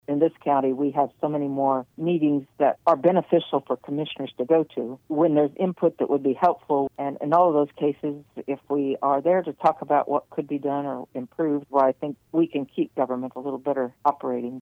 Commissioner Dee McKee, in an interview Wednesday with KMAN noted she was disappointed the sales tax question failed.